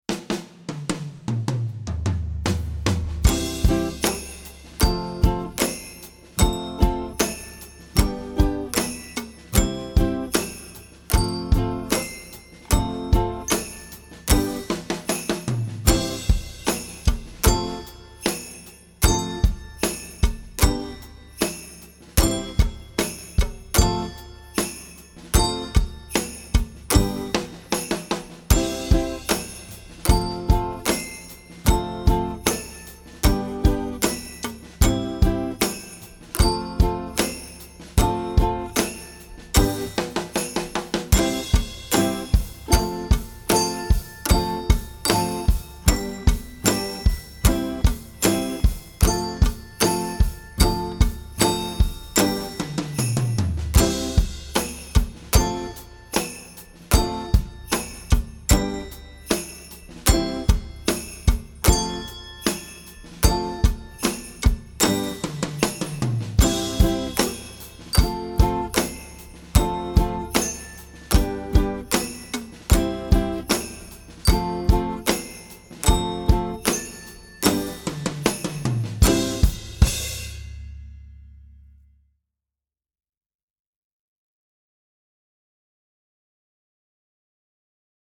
Kid Instruments Only Version
happy holiday tune
This is a track of only kid instruments.